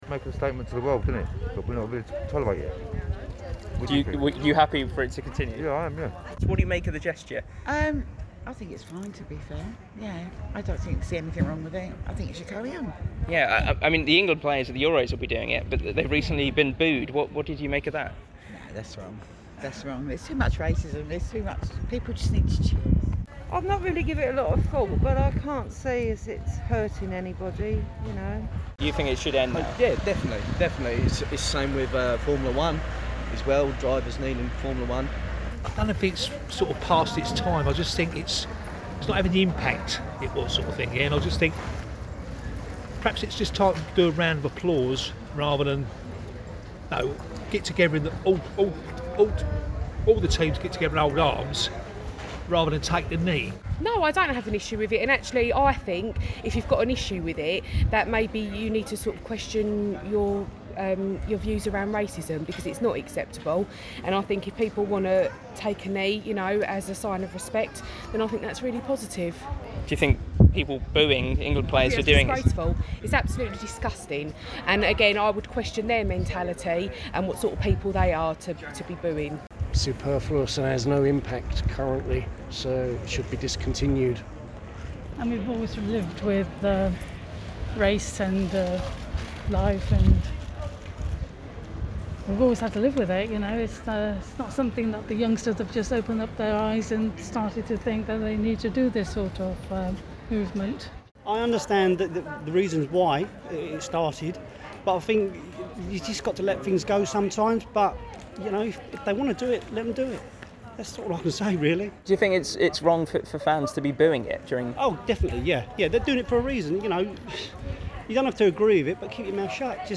LISTEN: We've been asking shoppers in Gillingham High Street what they think about 'taking the knee' - 10/06/21